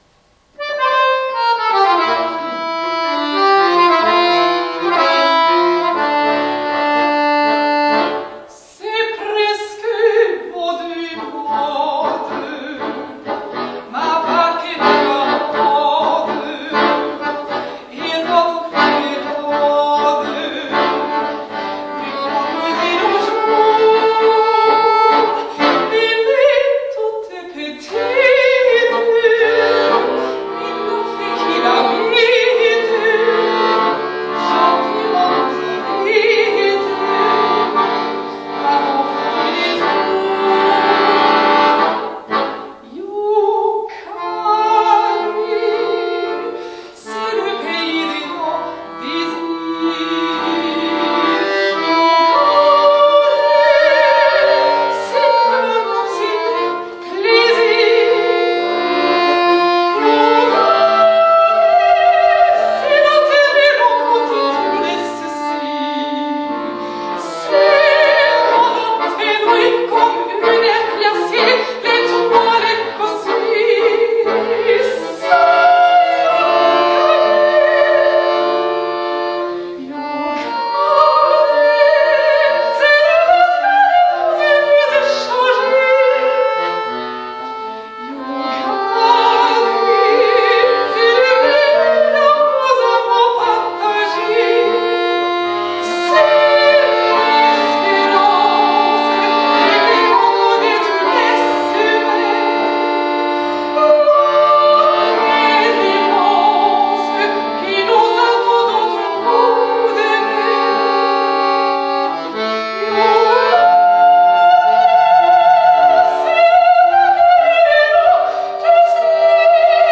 (Live- Mitschnitt)